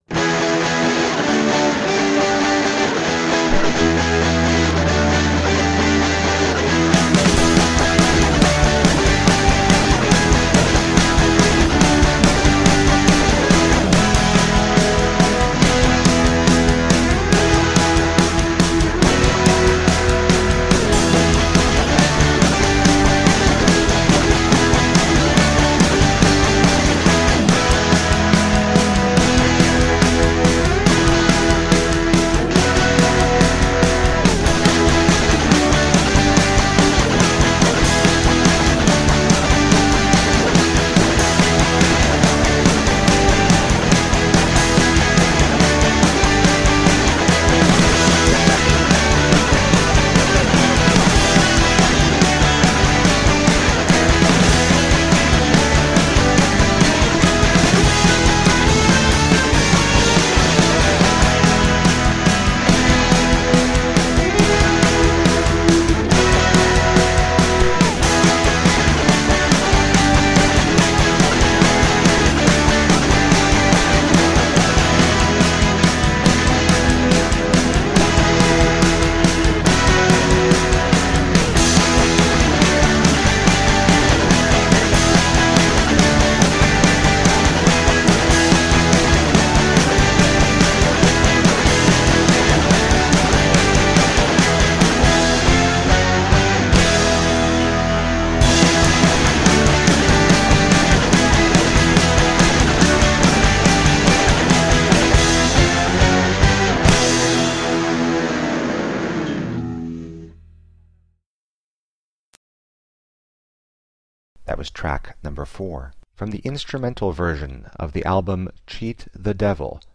Nippy post-punk retro rock with a bite.
Tagged as: Hard Rock, Rock, Instrumental